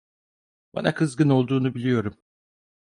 Pronounced as (IPA) /kɯz.ˈɡɯn/